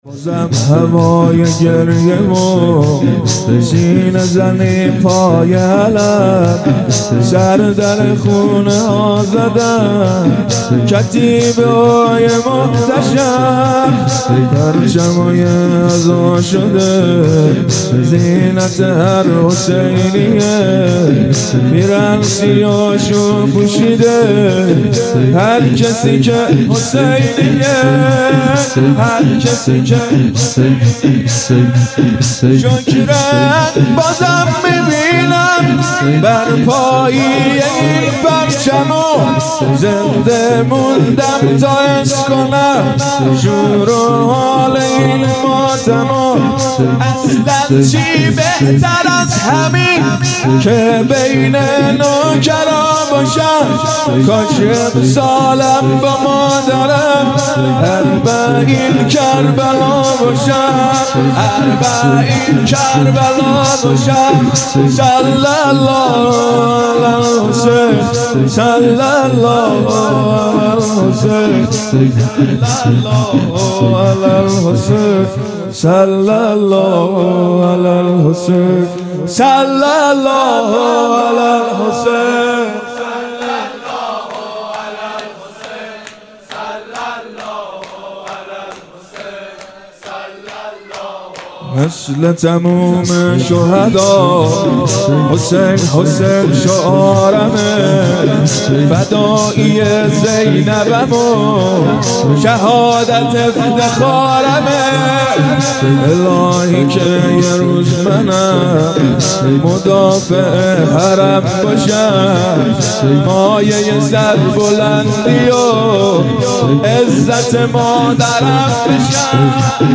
گزارش صوتی شب اول محرم الحرام ۱۴۳۹
شور | بازم هوای گریه و سینه زدن پای علم